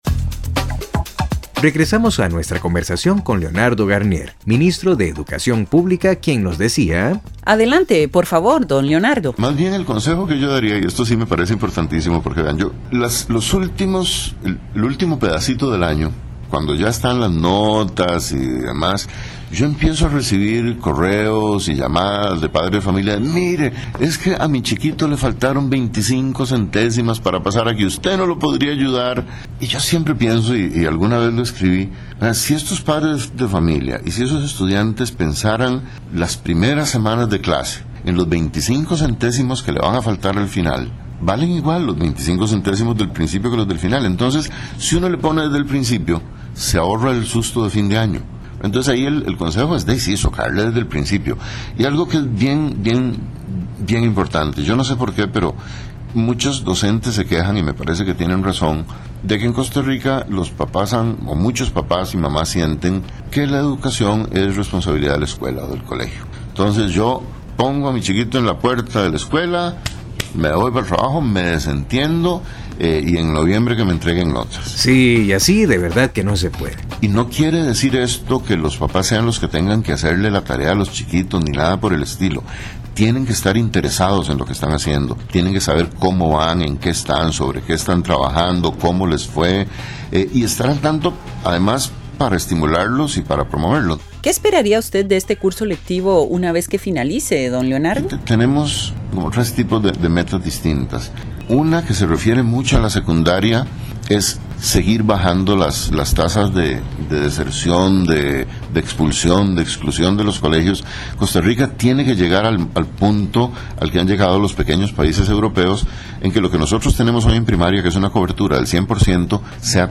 Regresamos a nuestra conversación con Leonardo Garnier, Ministro de Educación, quien nos decía…